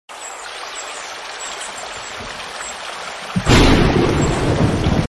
ASMR Rain In The Countryside Sound Effects Free Download